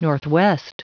Prononciation du mot northwest en anglais (fichier audio)
Prononciation du mot : northwest